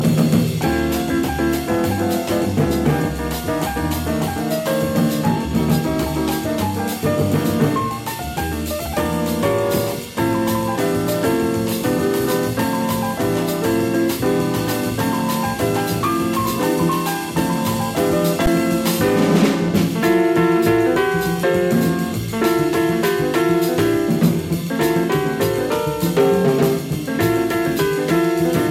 Master jazz pianist